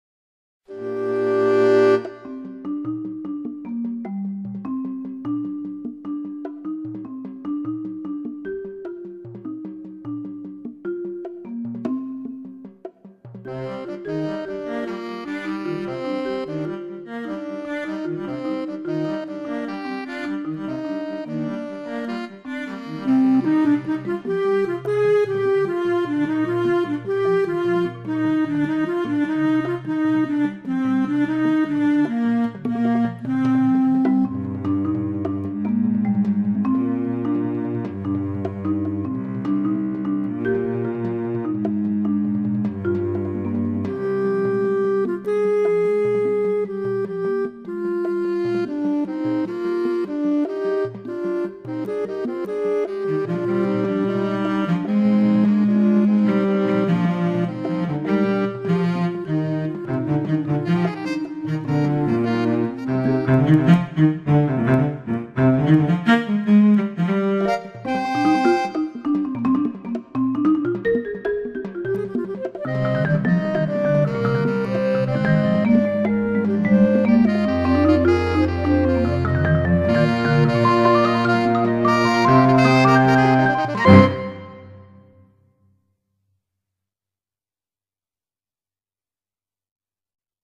Retrospecked (clarinet, tenor sax, marimba, drums, cello) – 2009
at Arizona State University’s Katzin Concert Hall